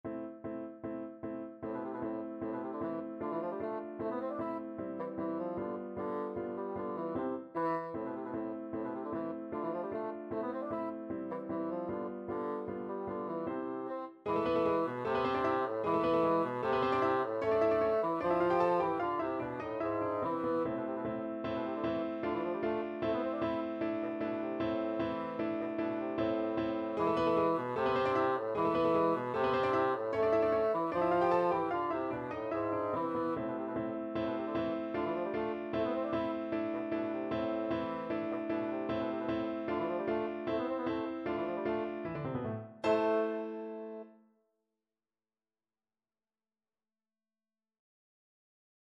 Classical Burgmuller, Friedrich Arabesque from 25 Progressive Pieces, Op.100 Bassoon version
A minor (Sounding Pitch) (View more A minor Music for Bassoon )
Allegro scherzando (=152) (View more music marked Allegro)
2/4 (View more 2/4 Music)
Classical (View more Classical Bassoon Music)